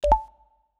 button_touch.mp3